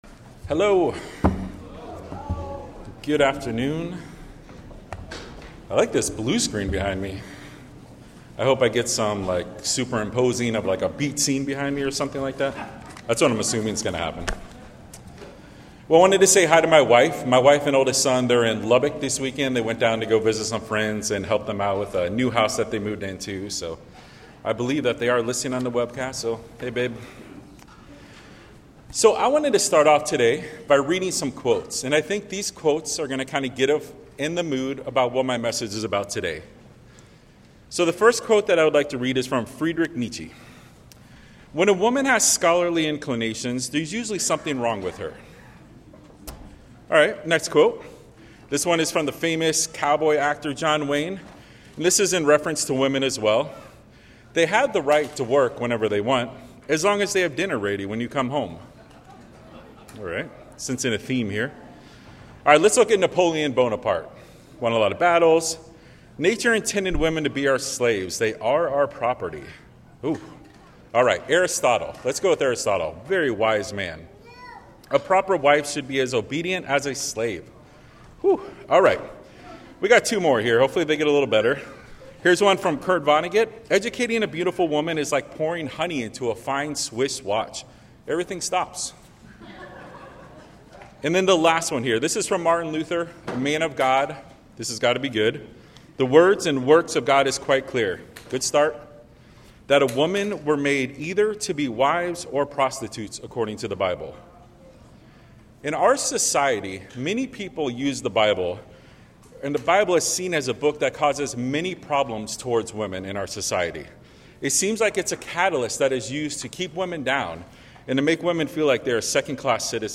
Sermons
Given in Dallas, TX